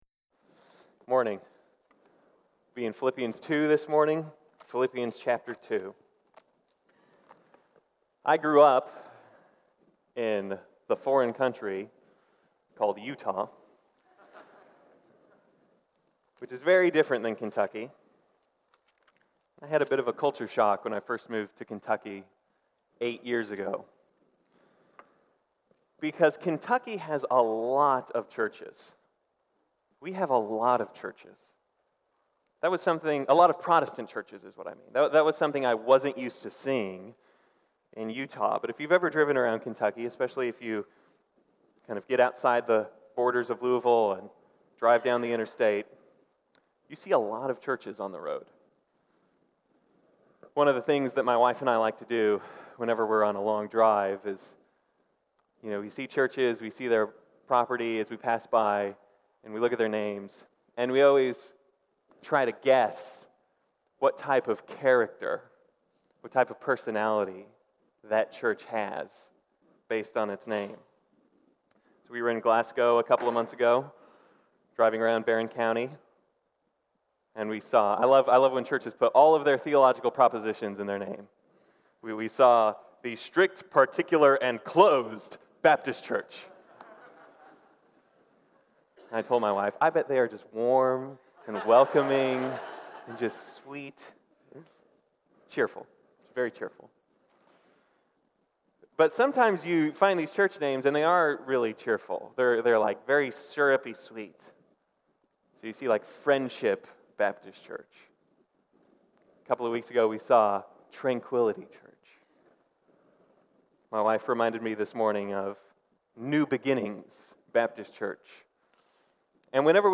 This sermon deals with the attitude church members need to be able to bear with and care for each other and their children in services.